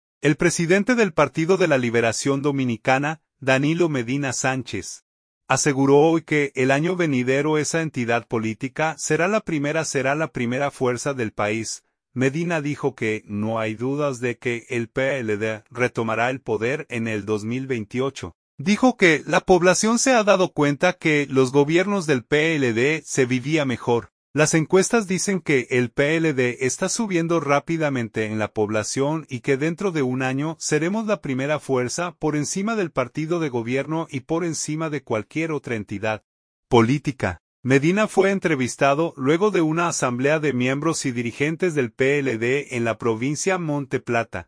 Medina fue entrevistado luego de una asamblea de miembros y dirigentes del PLD en la provincia Monte Plata.